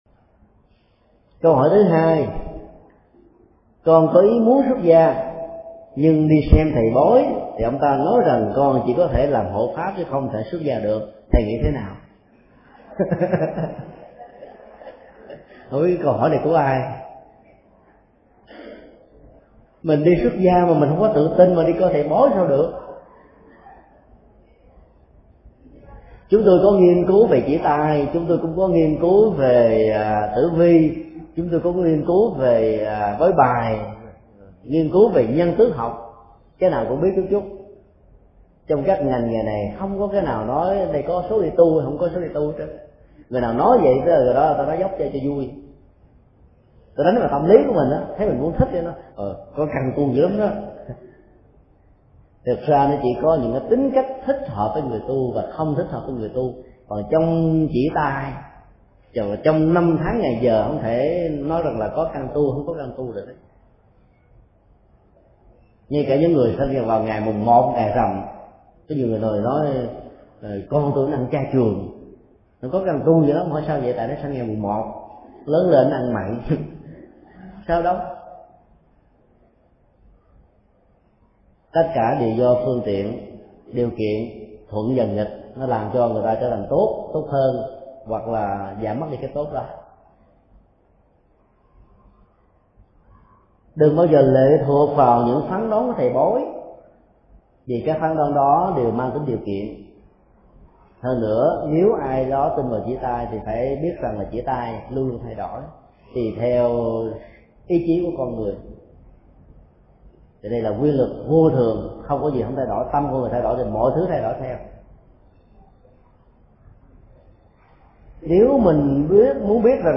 Vấn đáp: Mê tín dị đoan khi đi xuất gia